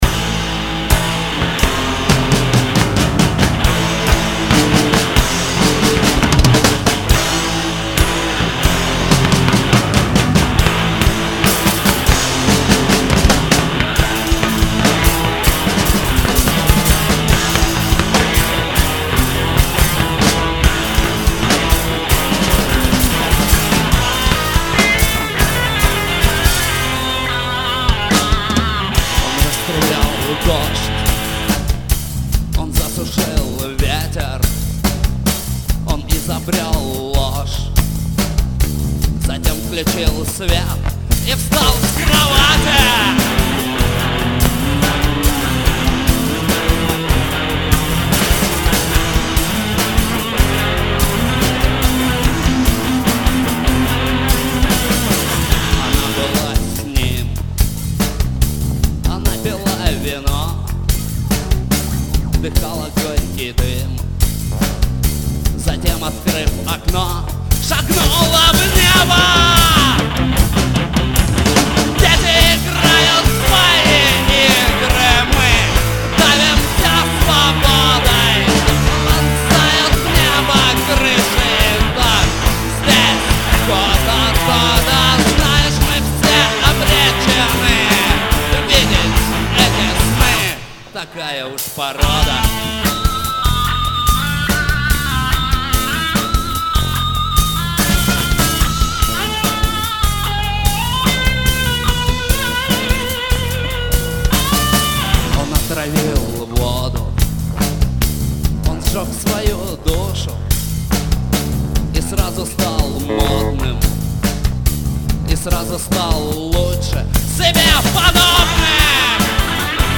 Запись с концерта в г. Багратионовск.